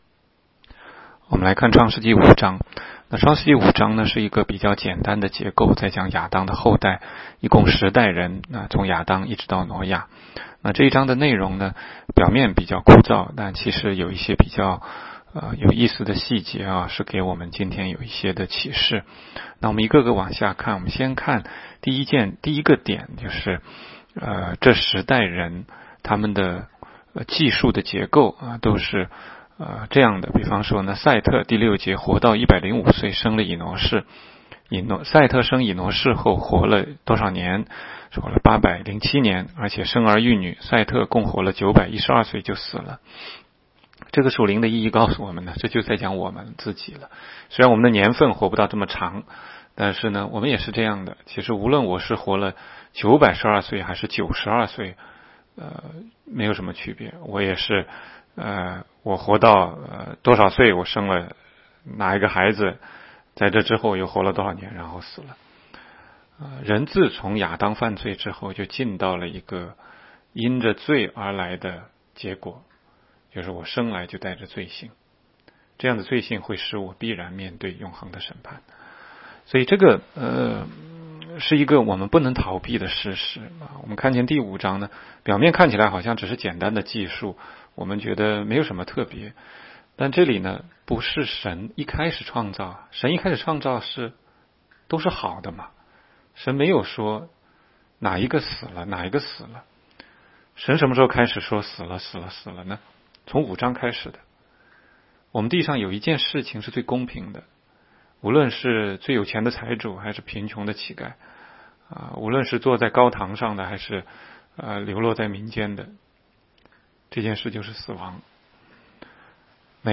16街讲道录音 - 每日读经-《创世记》5章